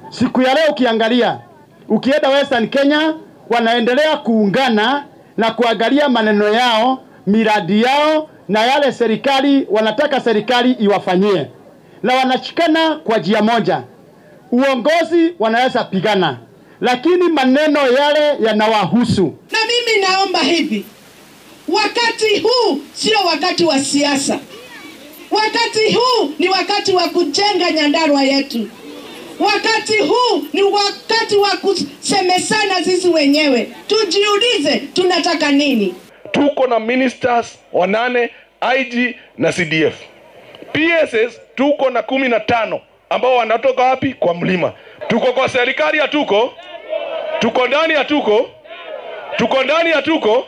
Xildhibaanka laga soo doorto bariga Laikipia Mwangi Kiunjuri ayaa ugu baaqay hogaamiyayaasha gobolka Mt Kenya inay joojiyaan aflagaadada iyo siyaasadaha isdiidan, isagoo ku booriyay inay diirada saaraan wax ka qabashada arimaha sida tooska ah u taabanaya muwaadiniinta. Isagoo ka hadlayay laba xafladood oo lacag uruurin ah oo ka dhacay ismaamulka Nyandarua, Kiunjuri wuxuu carabka ku adkeeyay baahida loo qabo midnimada iyo hogaaminta horumarka ku dhisan.